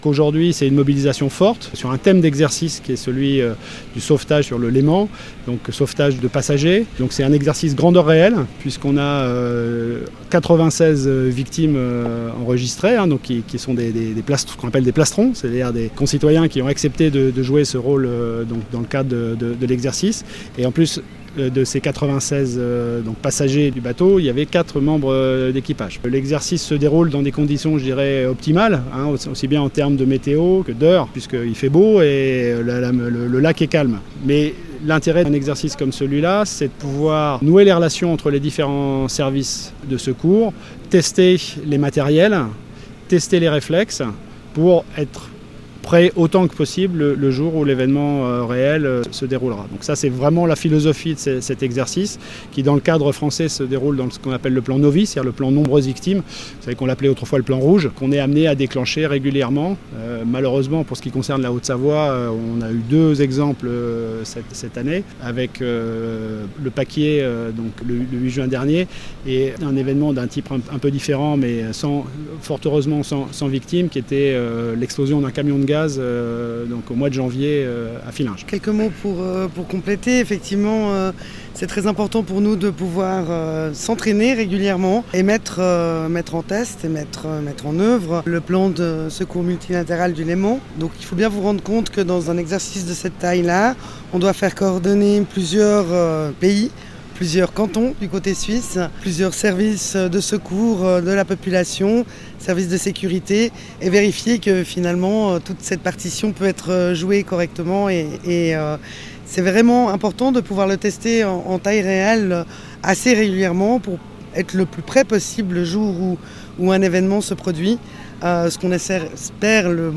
De nombreuse victimes après une explosion sur un bateau de la CGN, ce mardi 3 octobre (interview)
le Préfet de la Haute-Savoie, Yves Le Breton, et la Conseillère d'Etat du Canton de Genève, Carole-Anne Kast.